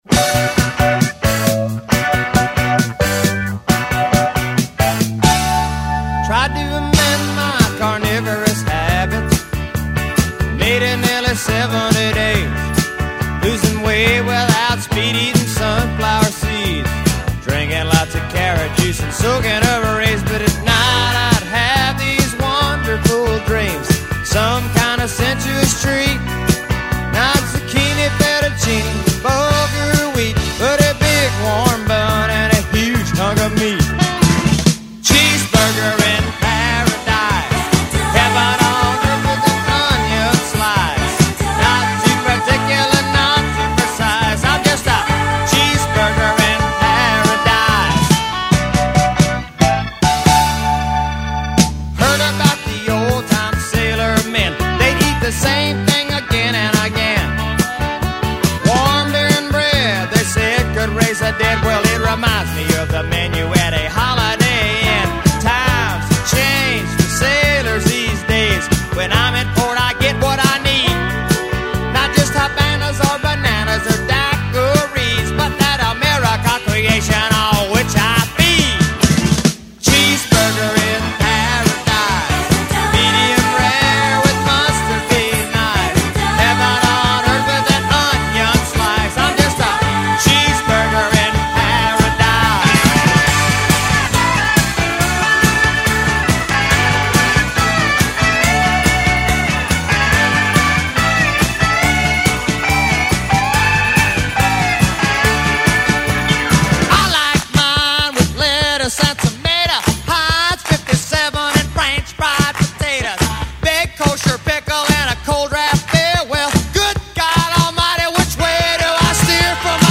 country singer